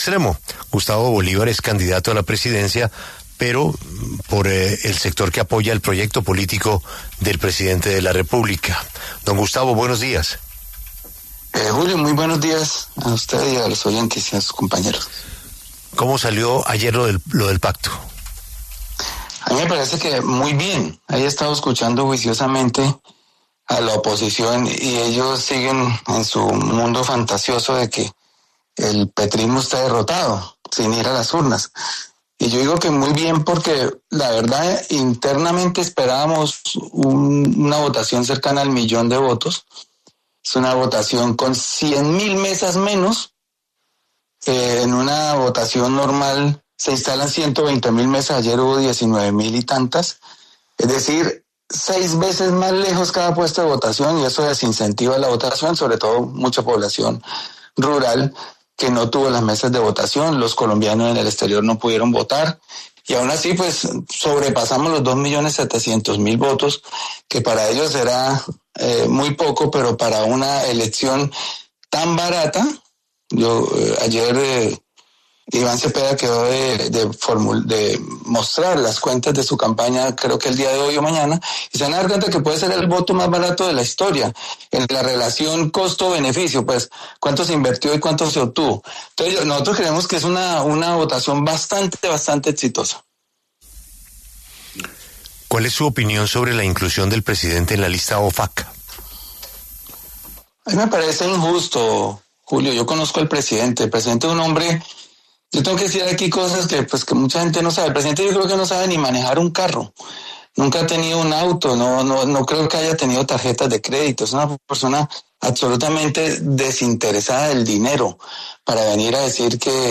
Gustavo Bolívar, candidato a la Presidencia, pasó por los micrófonos de La W y se refirió al triunfo de Iván Cepeda como ganador de la consulta presidencial del Pacto Histórico.